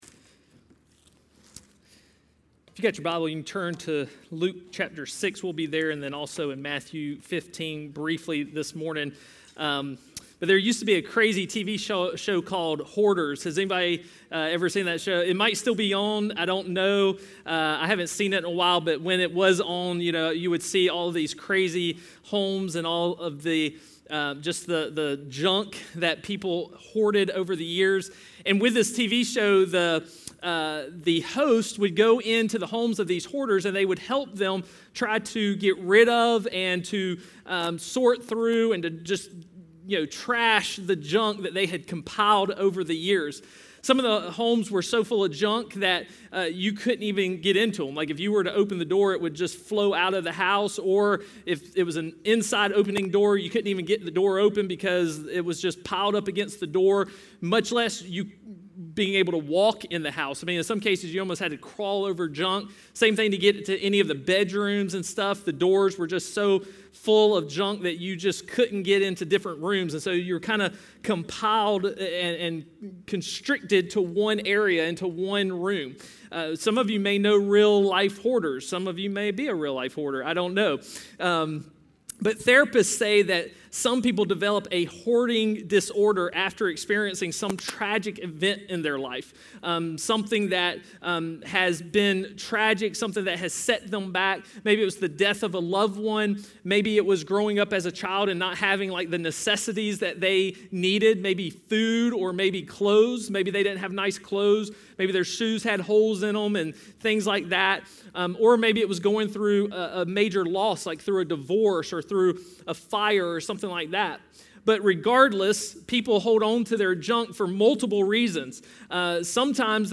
A message from the series "Bold."